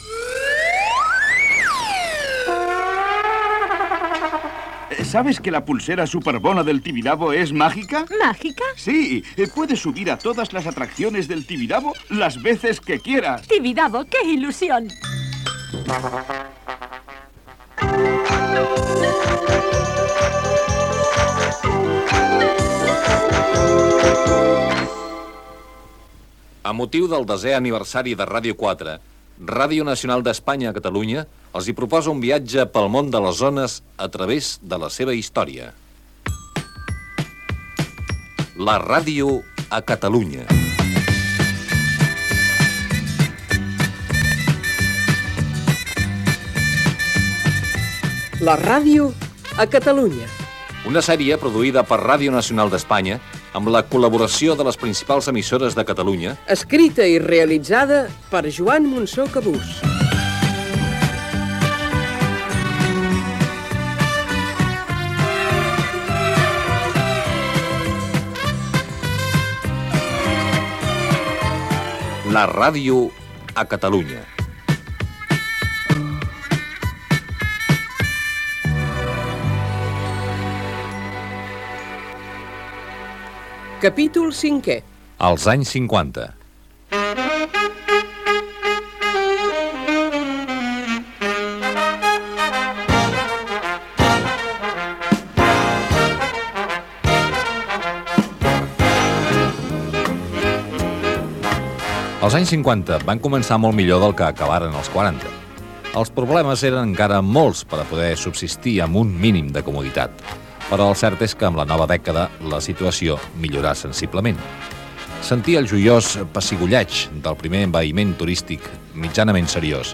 Publicitat, indicatiu, careta del programa, presentació del capítol 5è dedicat a la dècada de 1950
Divulgació